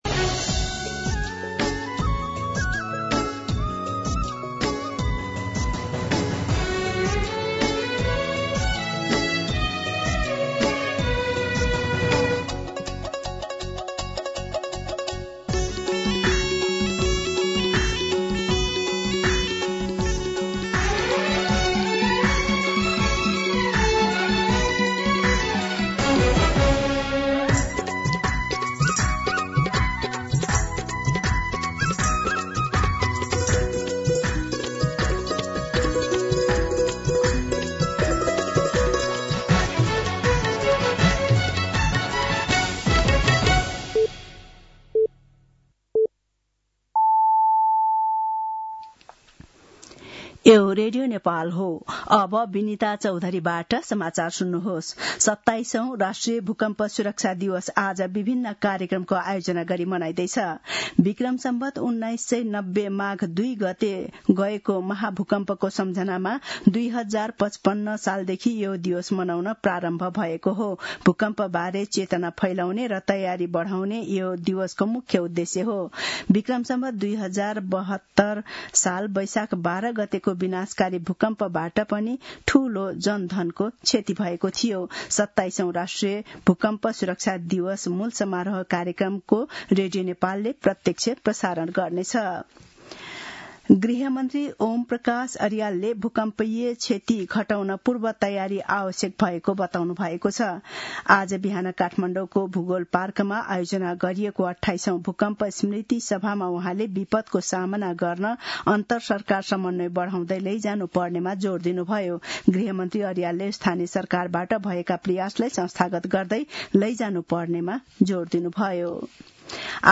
दिउँसो १ बजेको नेपाली समाचार : २ माघ , २०८२